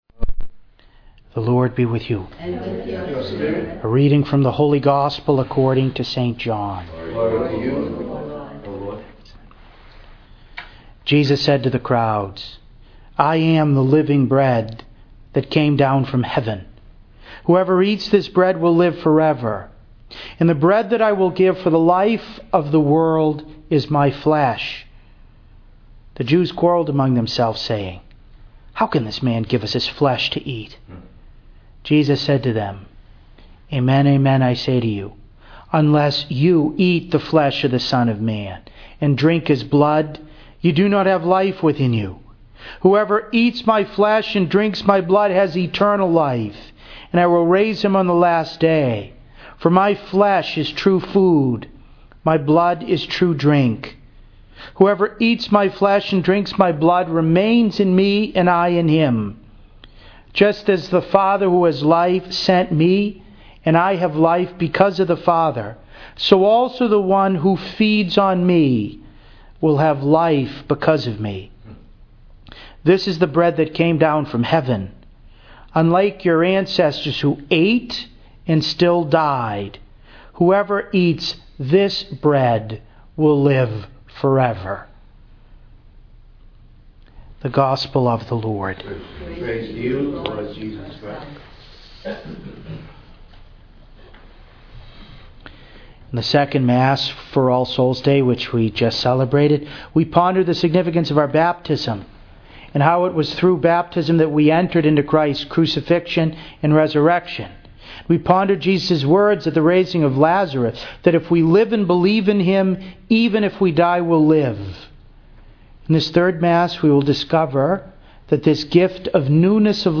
Third of Three Masses